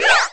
girl_toss_phone.wav